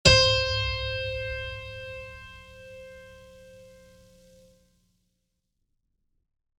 HardAndToughPiano
c4.mp3